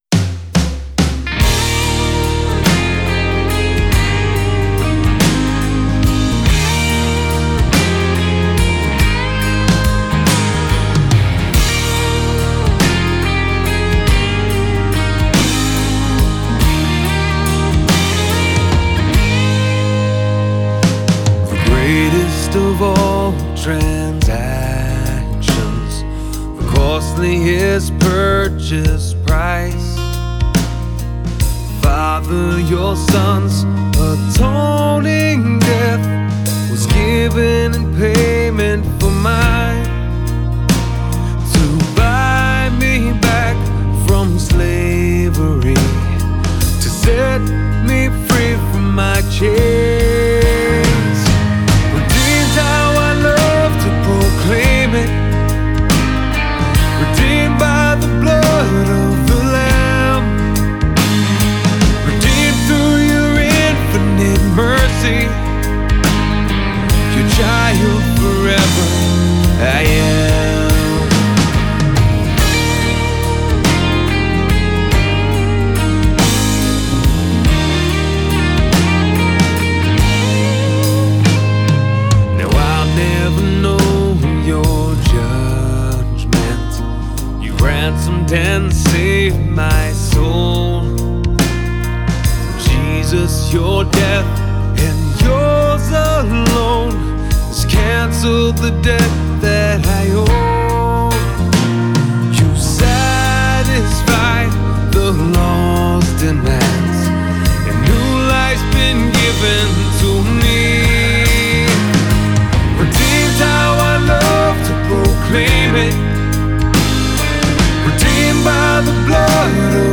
Cross-Centered Worship